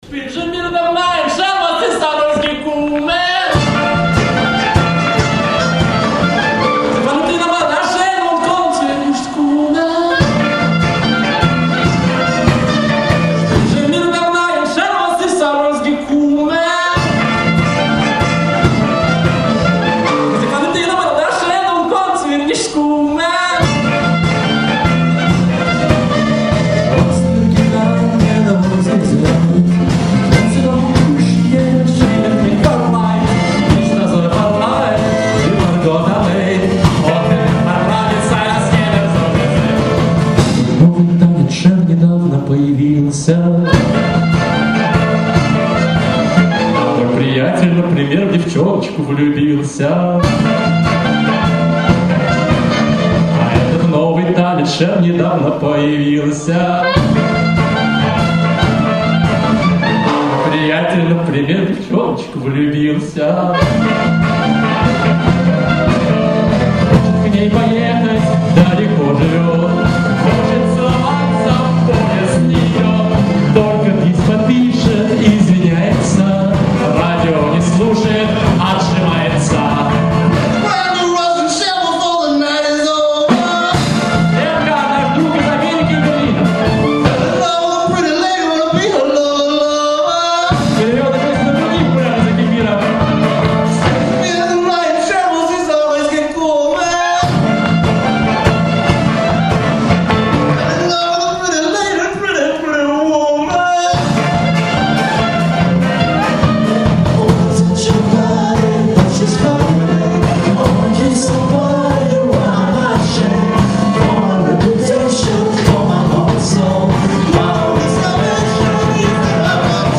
Четвертый международный фестиваль еврейской музыки
фестиваль клезмерской музыки